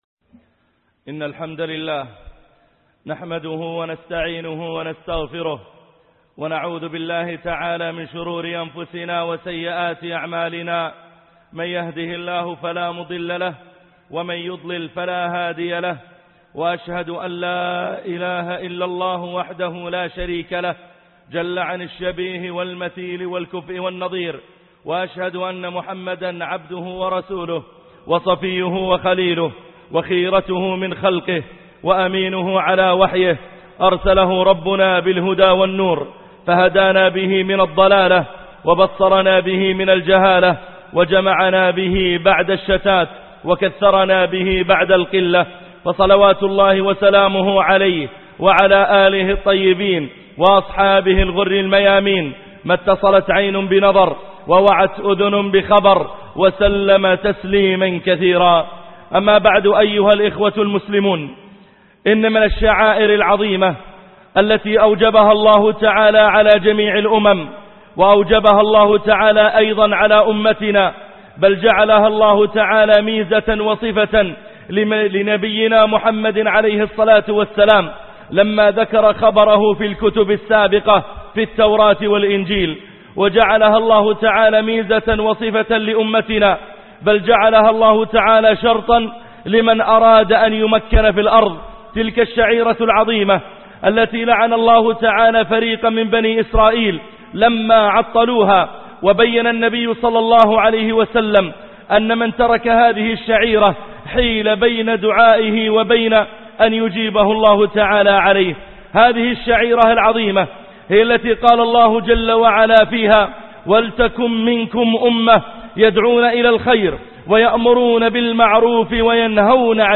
حقوق الآمرين بالمعروف - خطب الجمعة - الشيخ محمد العريفي